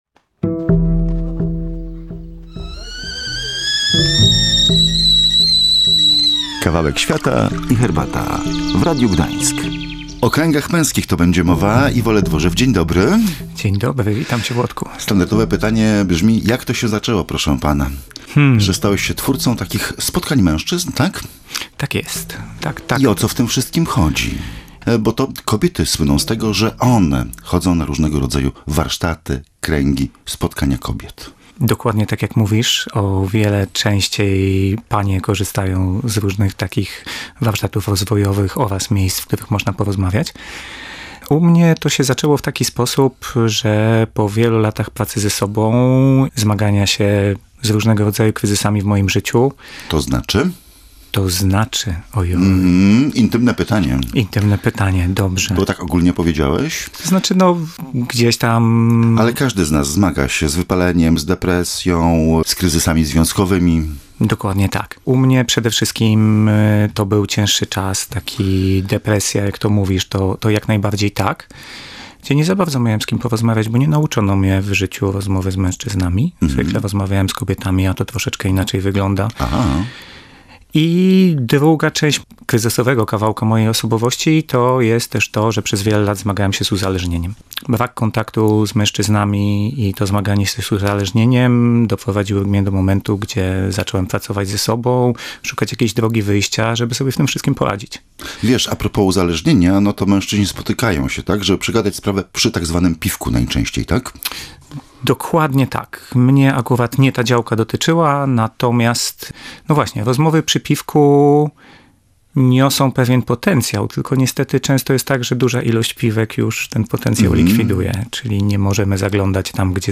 Posłuchaj rozmowy o męskich kręgach: